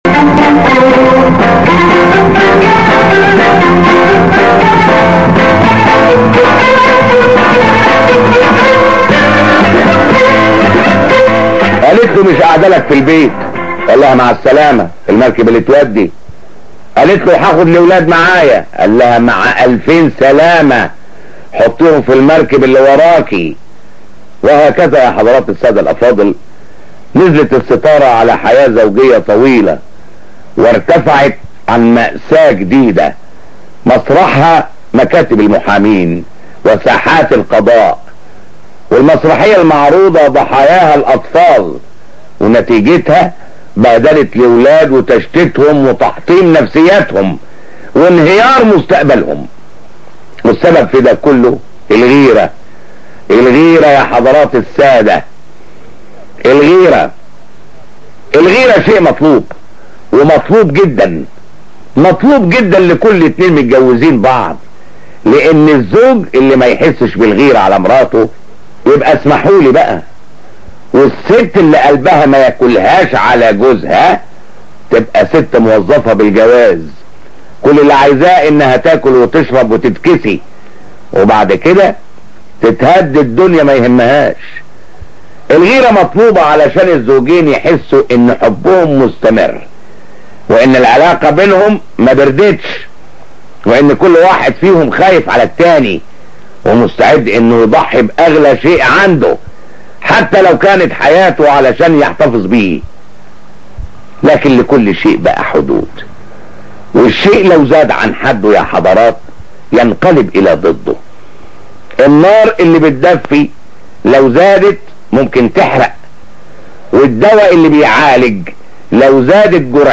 مقدم البرنامج دا هو الفنان الكبير الراحل فؤاد المهندس وهو مشهور بقدرته العالية على الإضحاك لأنه في الأصل ممثل كوميدي ومسرحي من طراز فريد…